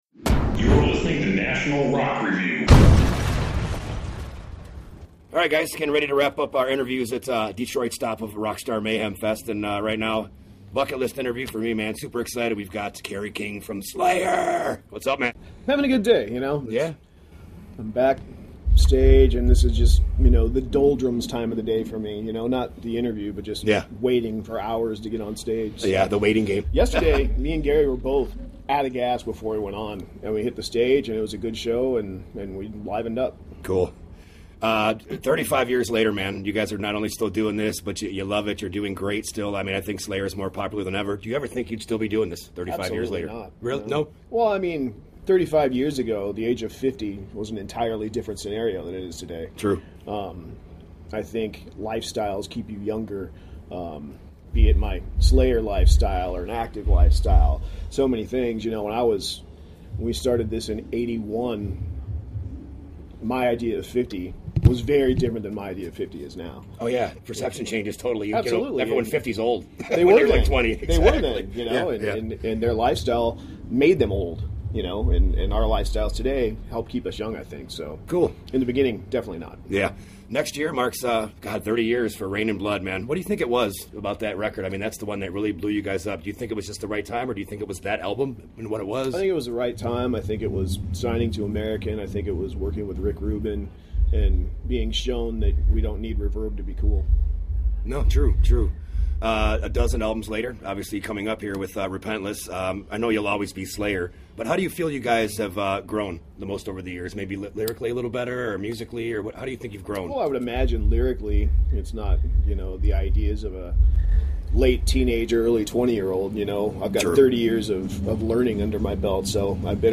Kerry King from Slayer talks to National Rock Review at the Detroit area stop of this year’s Rockstar Energy Drink Mayhem Fest.
The interview with Kerry King from Slayer: